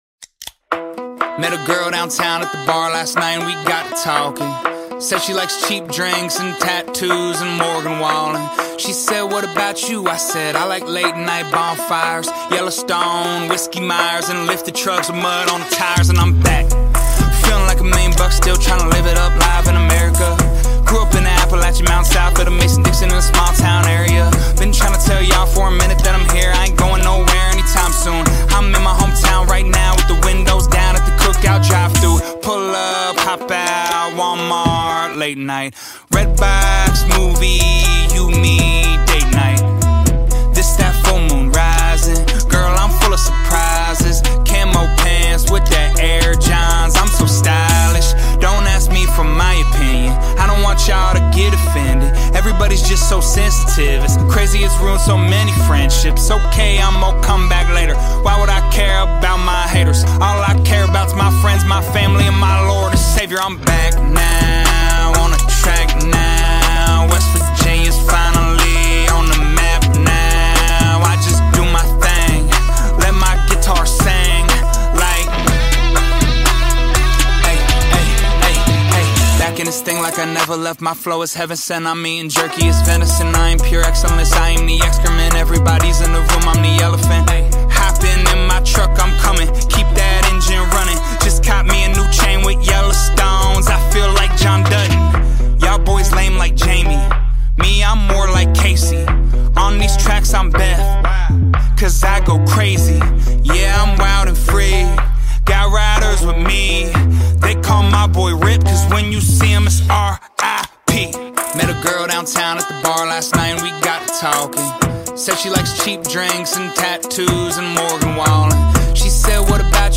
BPM122
Audio QualityPerfect (High Quality)
It has a good rhythm and is quite catchy.